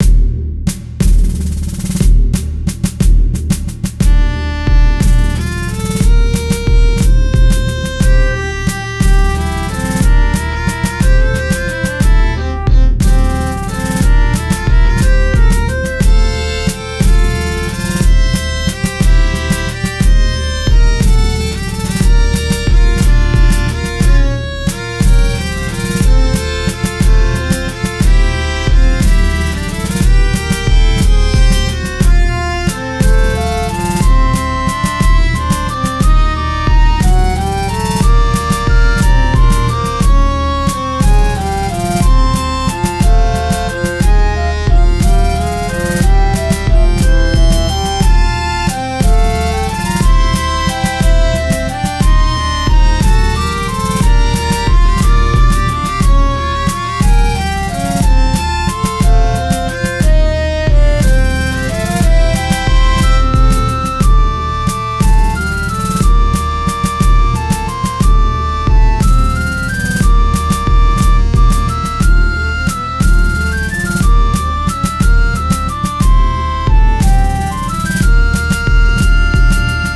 Palavras-chave: Jogos educacionais; Trilha original
Resumo: Trilha sonora criada para fase "Pirata